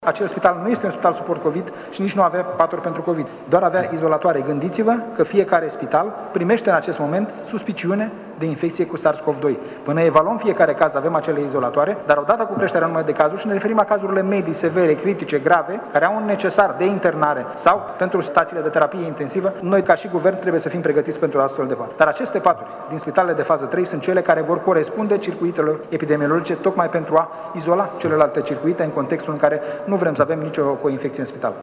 Prezent astăzi la Iași ministrul Sănătății, Nelu Tătaru, a declarat că nu se impune deschiderea unei anchete la Spitalul de Copii din Iași, deoarece unitatea medicală nu se află în categoria spitalelor suport COVID.